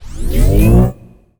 sci-fi_power_up_object_01.wav